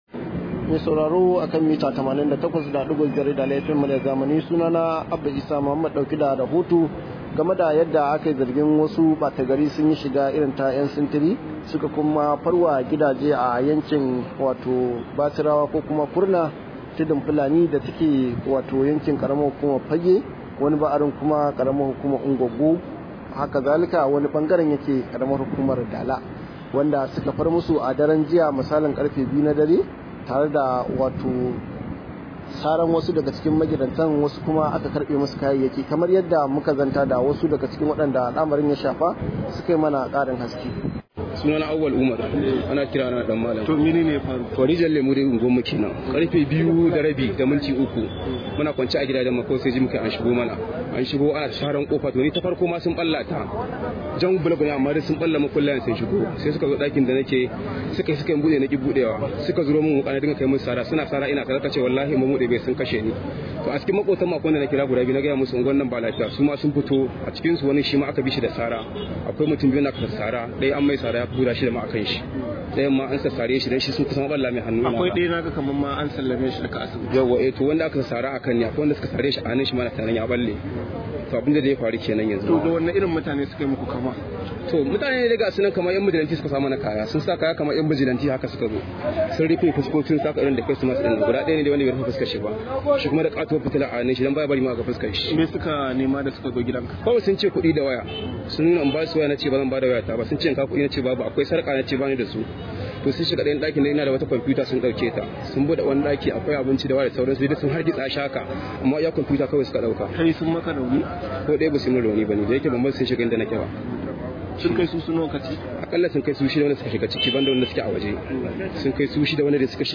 Rahoto: Wasu ɓata gari sun farwa al’ummar Bachirawa da sara